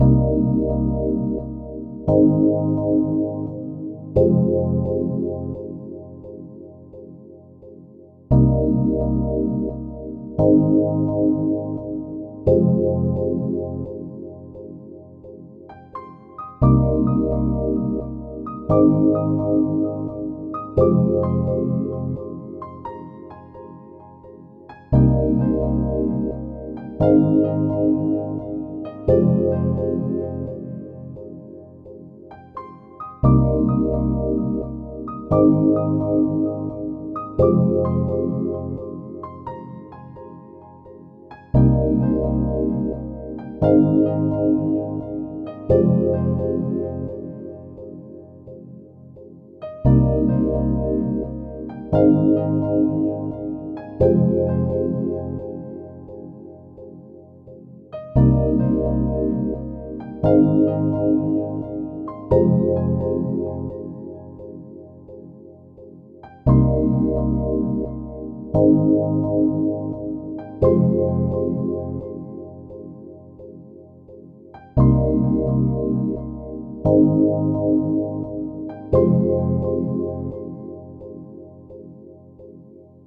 • Music requires/does smooth looping